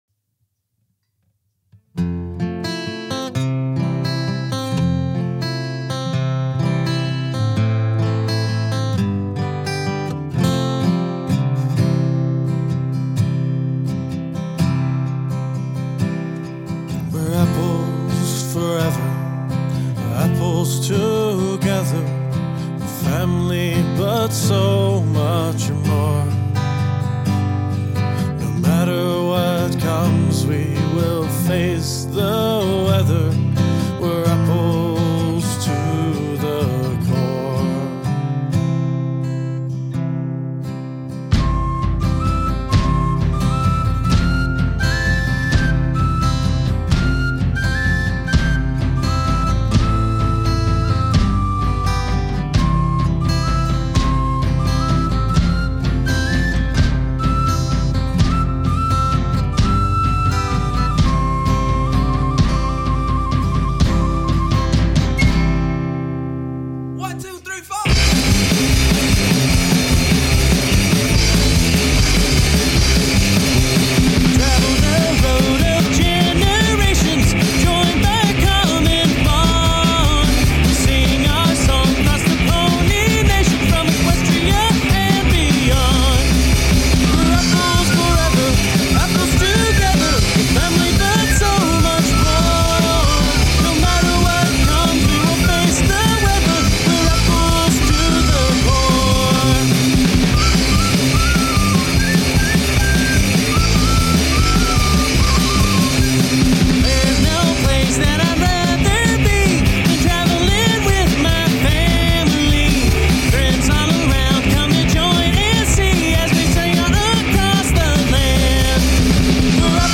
celtic punk rock cover
a rock band
Acoustic Guitar, Lead Vocals
Electric Guitar
Bass Guitar
Drums, Percussion, Backing Vocals
Tin whistle